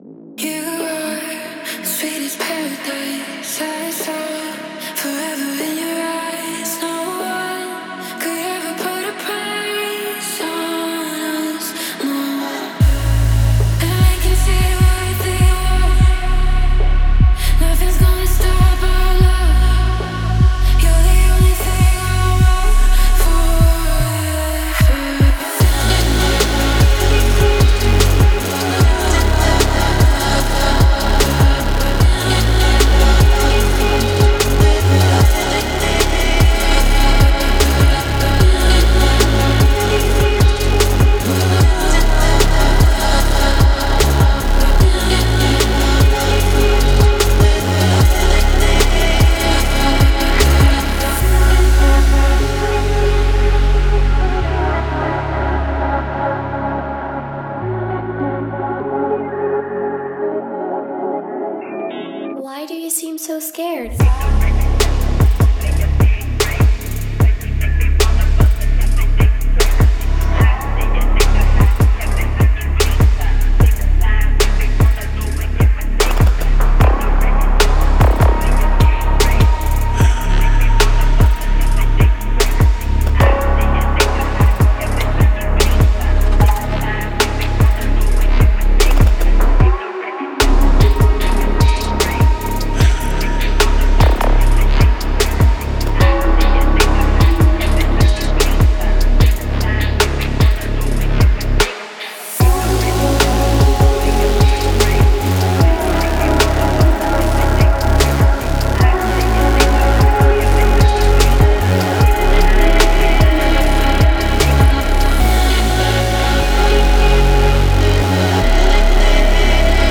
Фонк треки
Phonk